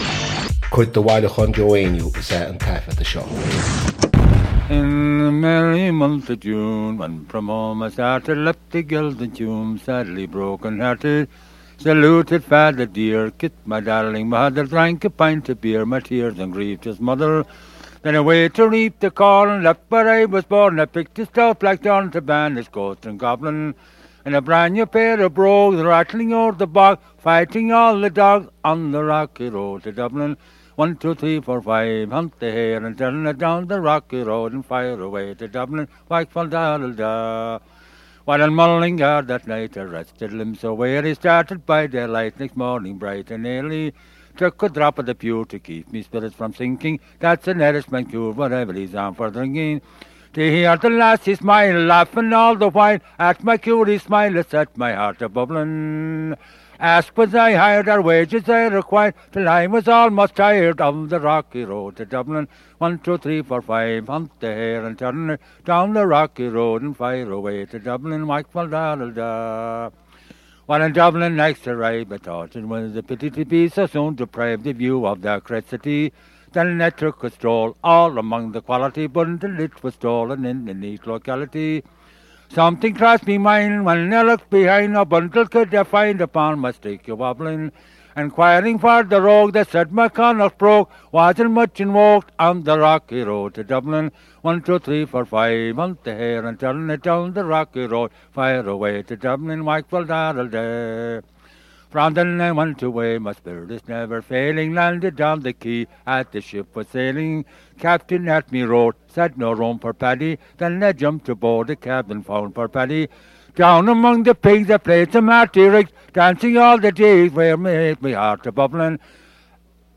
• Catagóir (Category): song.
• Ainm an té a thug (Name of Informant): Joe Heaney.
• Ocáid an taifeadta (Recording Occasion): studio session.
On both occasions he broke down, the words escaping him during the second-to-last stanza.
The air is a popular slip-jig, recorded by Petrie (The Ancient Music of Ireland, p. 175; The Complete Petrie Collection, pp. 548 and 969); Joe also had a song in Irish, Tadhg na nGarantaí, which employed the same tune.